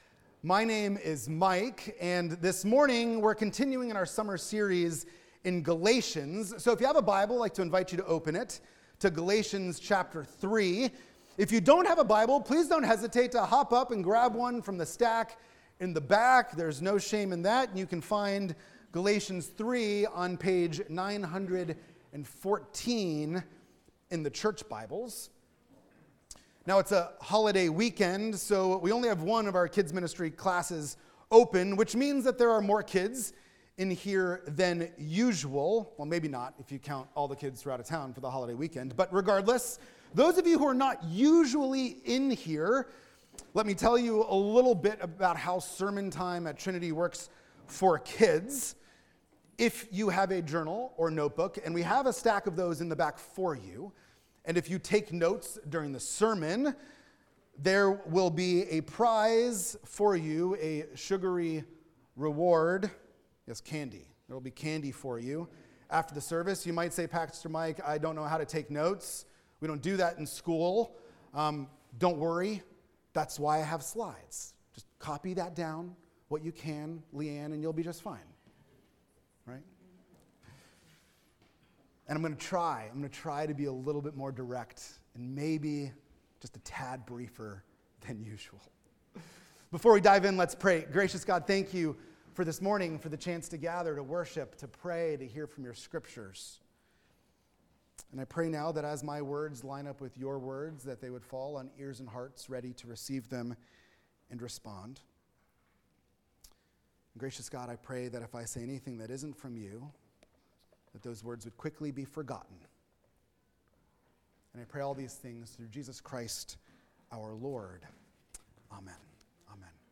Week 4 in the summer sermon series in Galatians at Trinity Church, Burke.